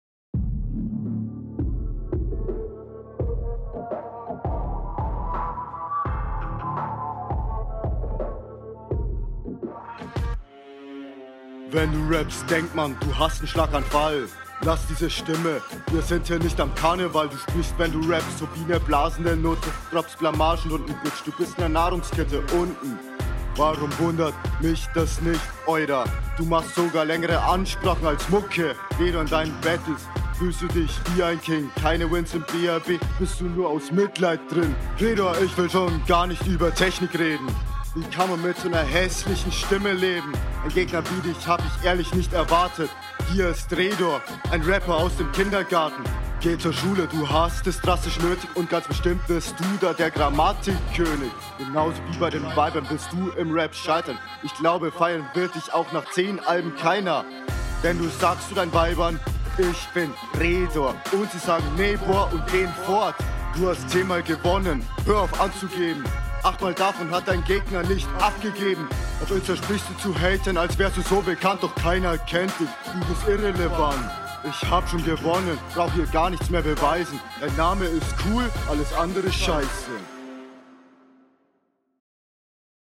Auf dem Beat kommst du besser klar.